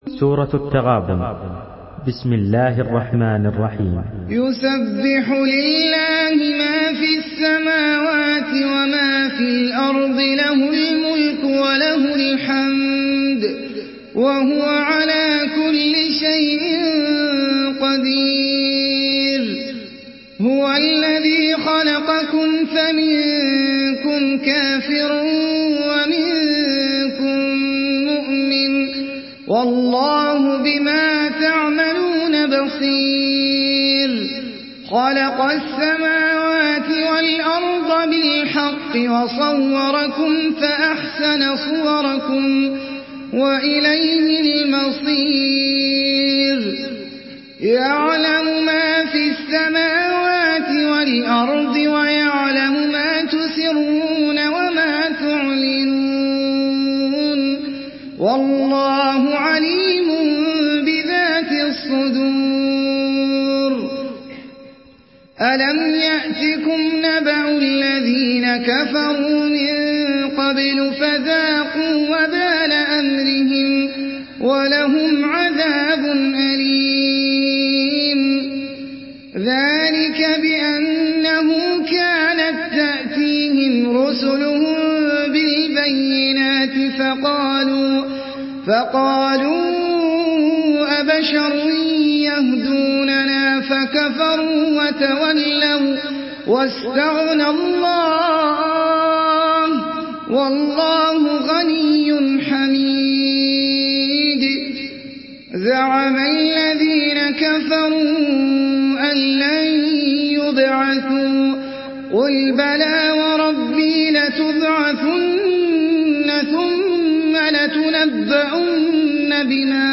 Surah At-Taghabun MP3 by Ahmed Al Ajmi in Hafs An Asim narration.
Murattal Hafs An Asim